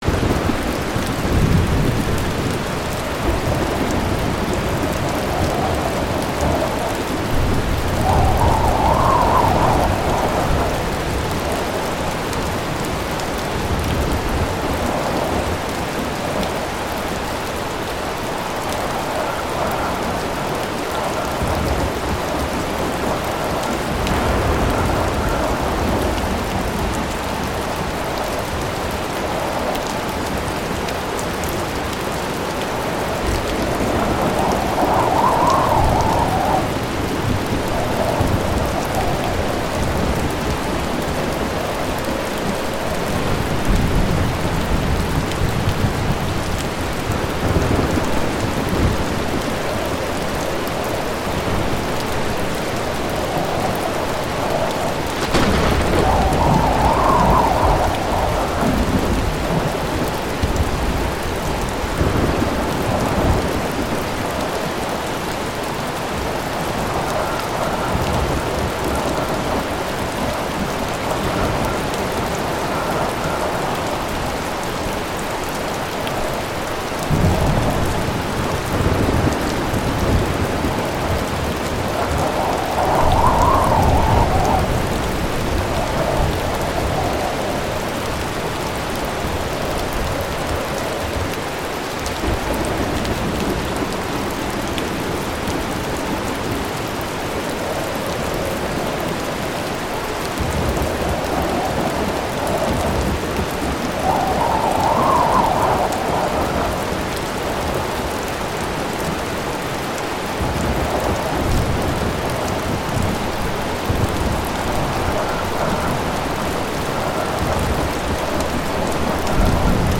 ENTSPANNENDE STILLE: Regengeräusch-Wald-Donnerstille ohne störenden Donner